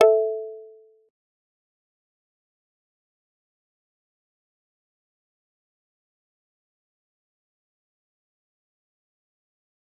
G_Kalimba-A4-pp.wav